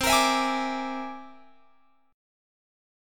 C Augmented 9th